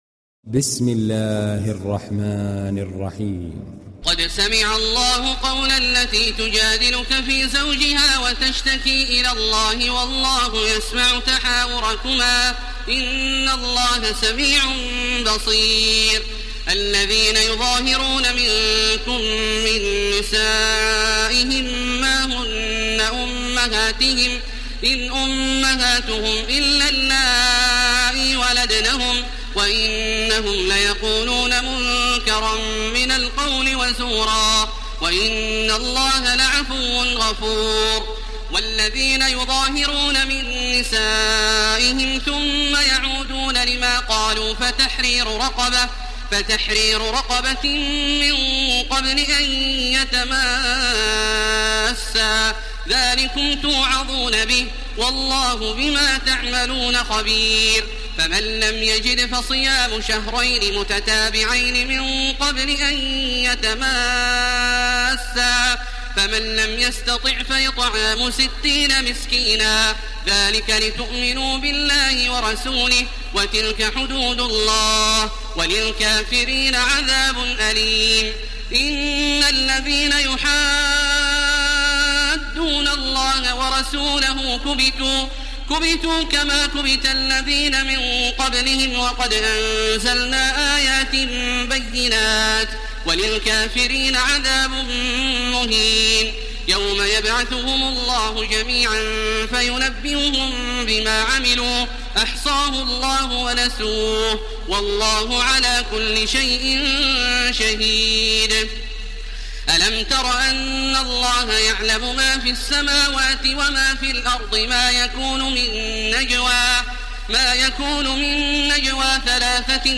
دانلود سوره المجادله تراويح الحرم المكي 1430